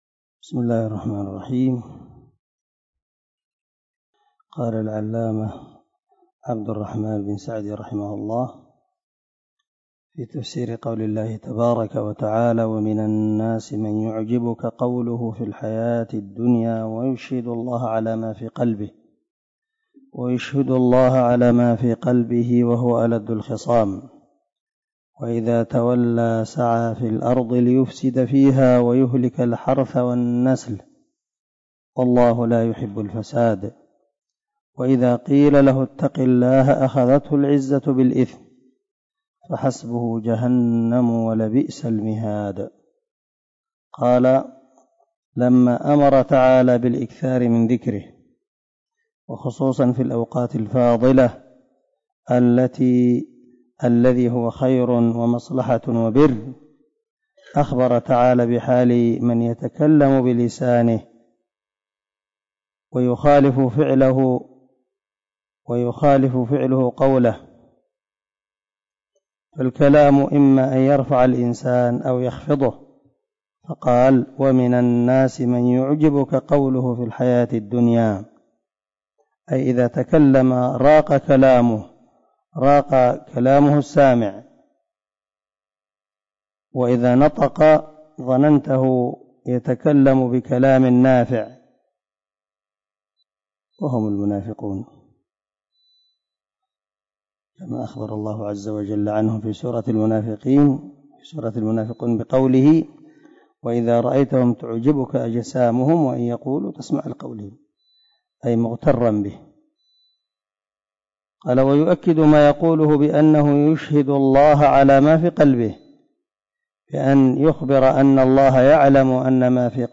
098الدرس 88 تابع تفسير آية ( 204 – 207 ) من سورة البقرة من تفسير القران الكريم مع قراءة لتفسير السعدي
دار الحديث- المَحاوِلة- الصبيحة.